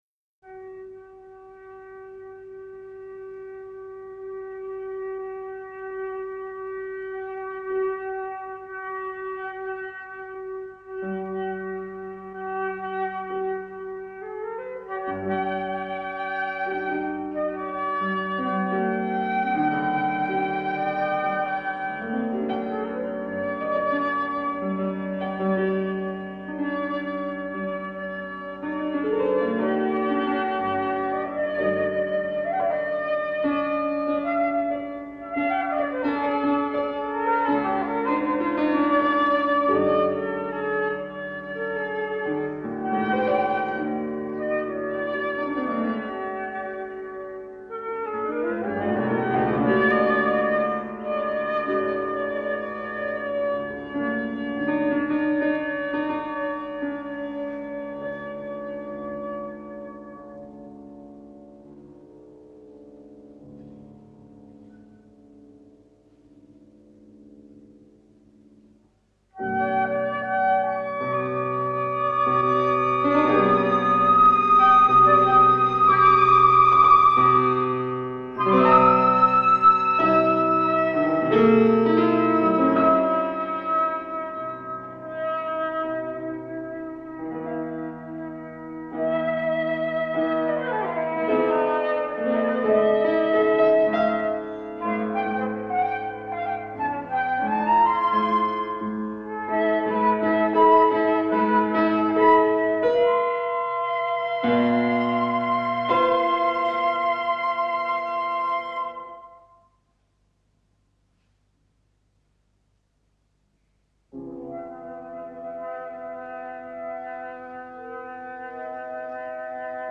pieza para dos flautas y piano
* live recordings